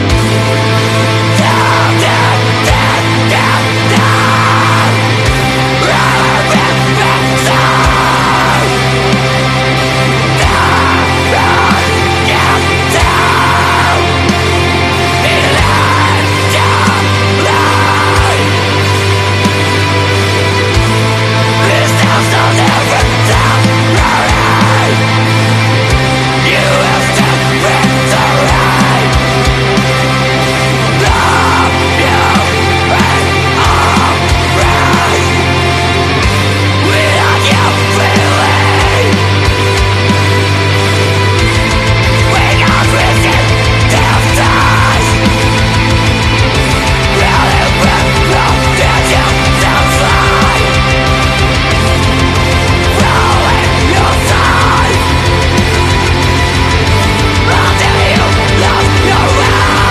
Genre : Atmospheric Black Metal